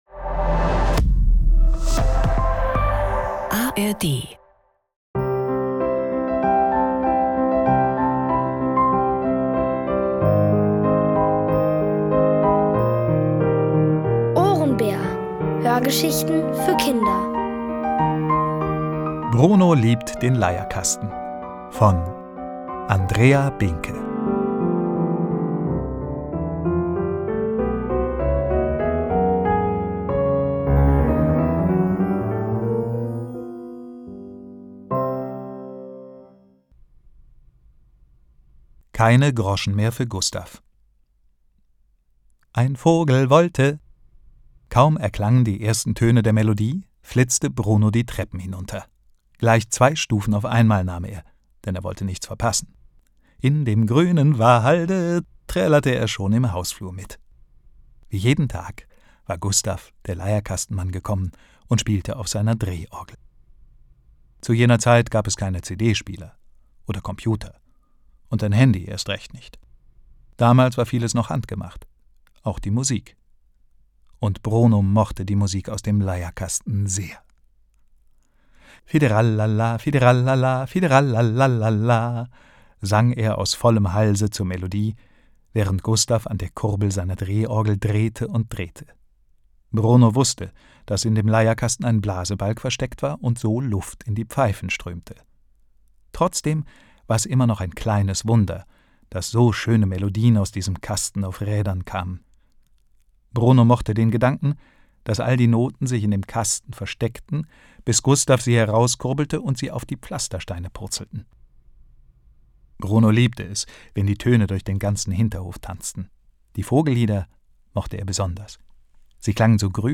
Bruno liebt den Leierkasten | Die komplette Hörgeschichte! ~ Ohrenbär Podcast